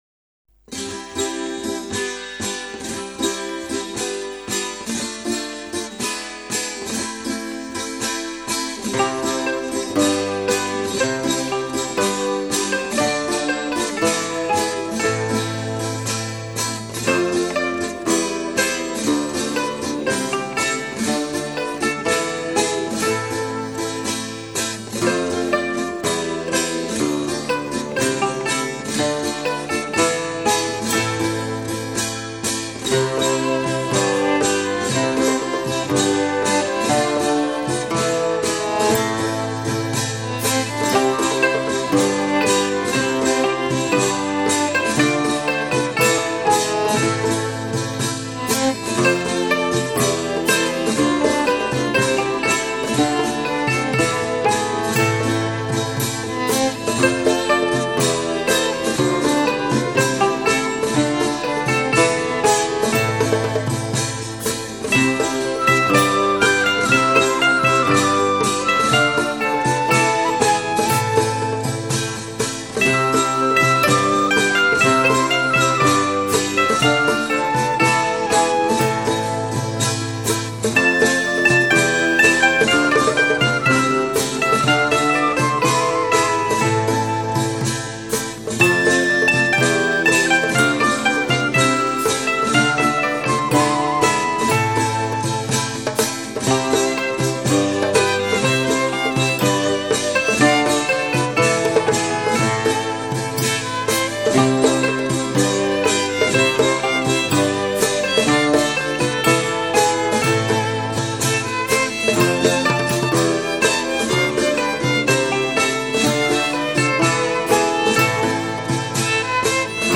A piece of Italian music